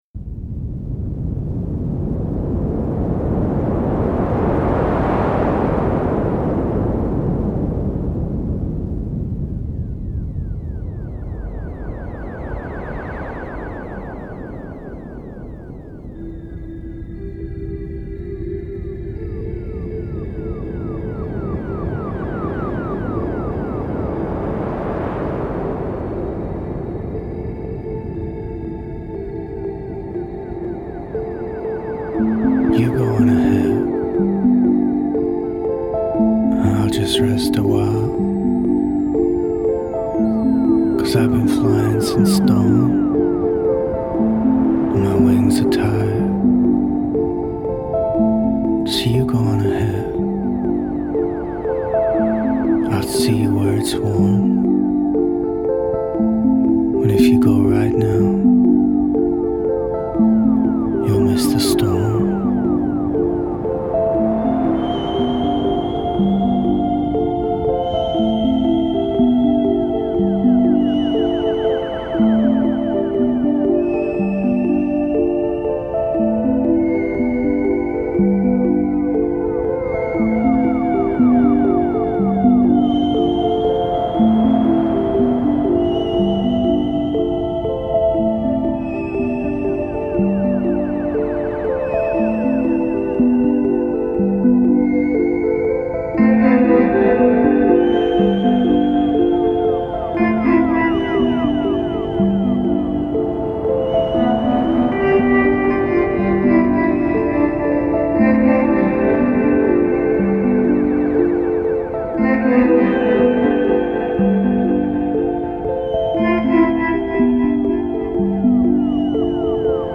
a particularly emotional highpoint